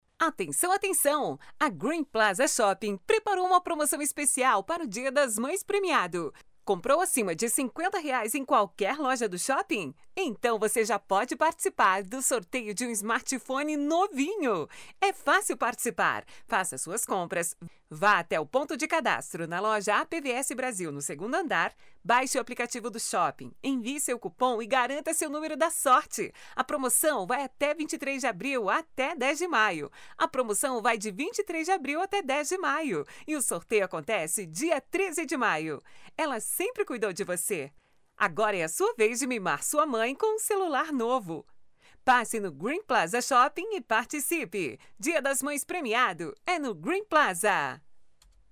MÃE PROMO SHOPING: